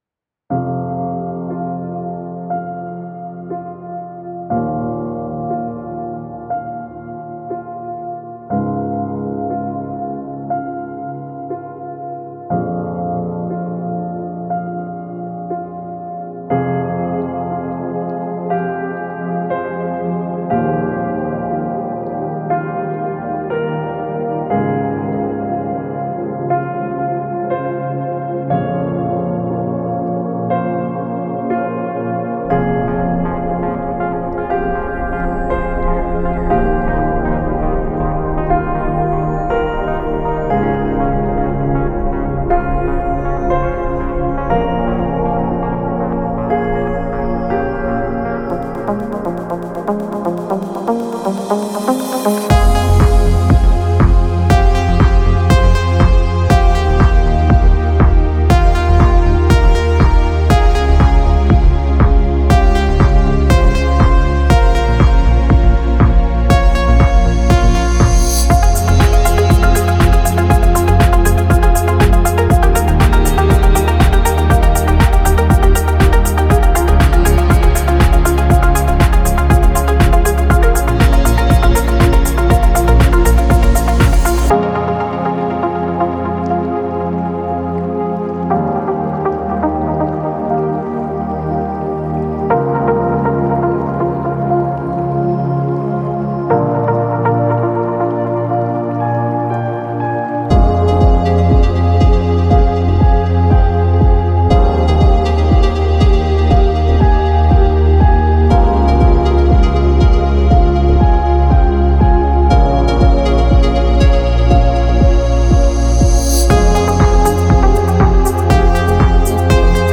טראק סטייל Deep House שלי ביוטיוב - ''Open Sky''
שלום לכולם, אחרי תקופת ספירת העומר ותקופה שלא שיתפתי דברים, אני שמח לשתף אתכם בקטע חדש שעשיתי, מוזיקה אלקטרונית עם צלילים נעימים ועמוקים, ואווירה טובה.
זה קטע התפתחות ממש שלב אחרי שלב, ואז במרכז טראק עדין, המוזיקה פה מתפתחת אבל תמיד שומרת על עדינות ולא פורצת יותר מידי, סטייל ‘‘Deep House’’